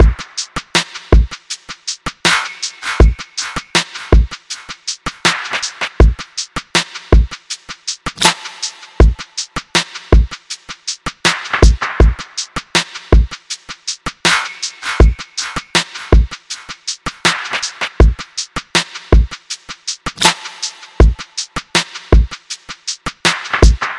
标签： 80 bpm Electronic Loops Drum Loops 4.04 MB wav Key : Unknown
声道立体声